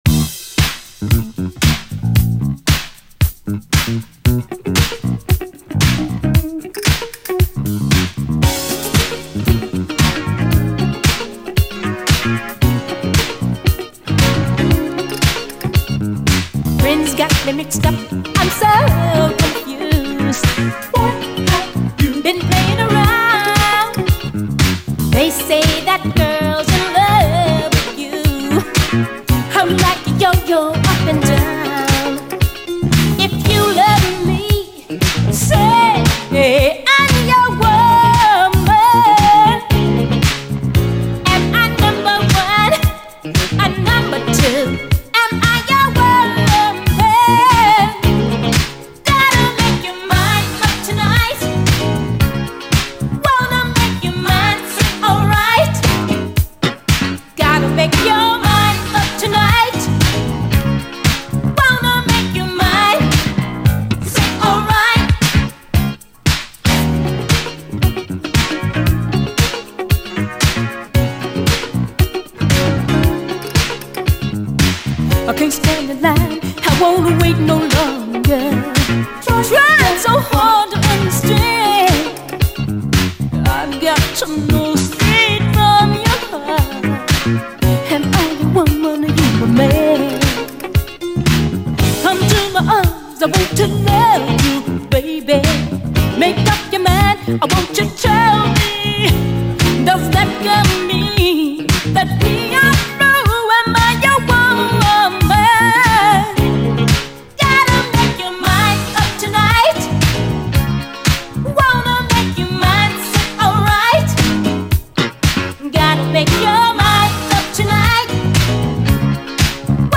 SOUL, 70's～ SOUL, DISCO, 7INCH
しなやかなNYディスコ〜モダン・ブギー・クラシック！
キリッと引き締まったグレイト・ダンサー！